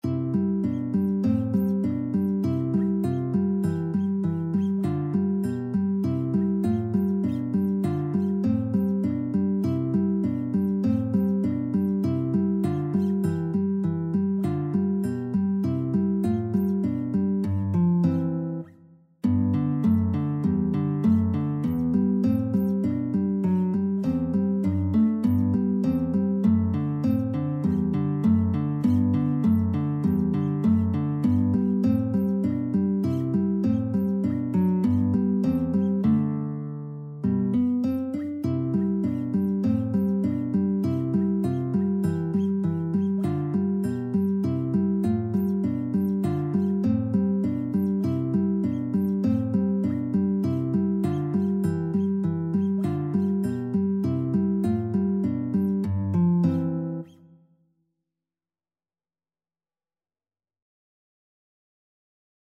Free Sheet music for Guitar
4/4 (View more 4/4 Music)
C major (Sounding Pitch) (View more C major Music for Guitar )
Guitar  (View more Easy Guitar Music)
Classical (View more Classical Guitar Music)